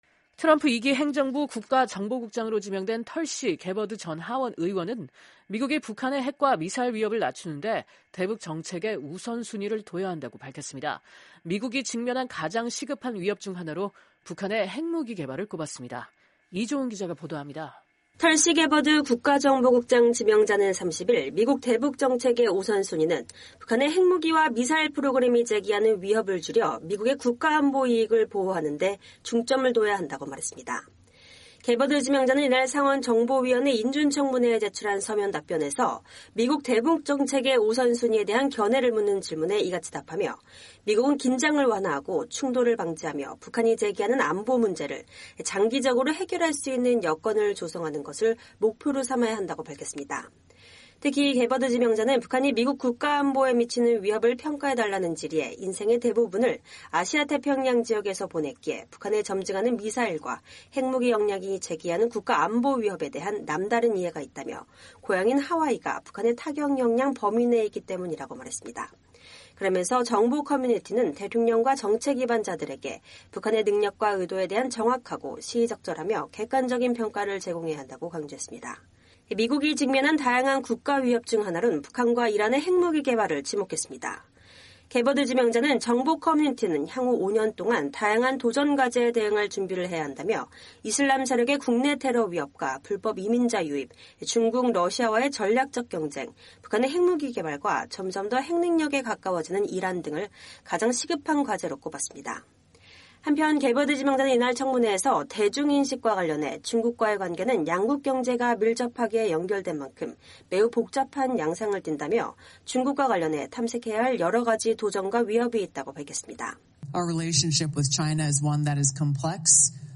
털시 개버드 국가정보국장(DNI) 지명자가 2025년 1월 30일 상원 정보위원회 인준청문회에서 발언하고 있다.